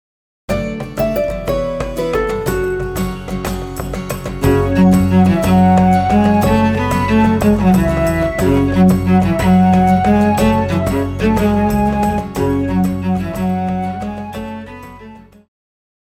Pop
Cello
Band
Instrumental
World Music,Electronic Music
Only backing